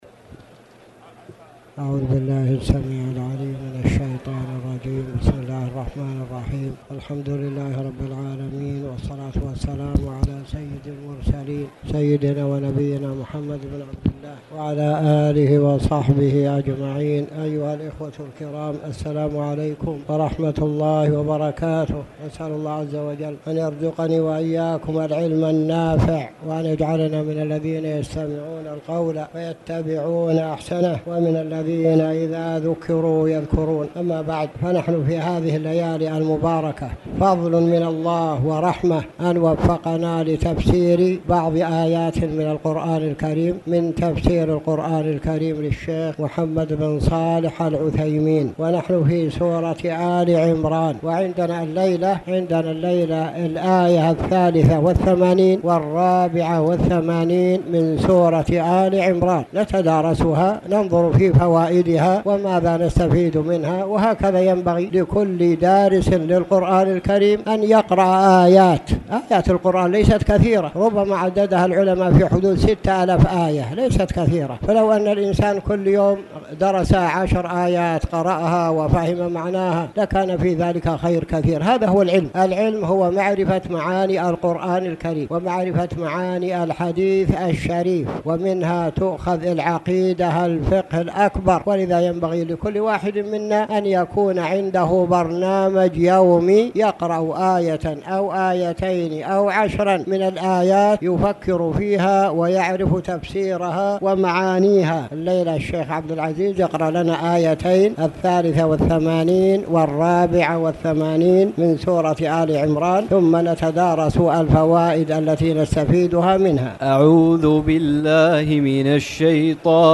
تاريخ النشر ٢٢ رمضان ١٤٣٨ هـ المكان: المسجد الحرام الشيخ